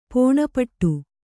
♪ pōṇapaṭṭu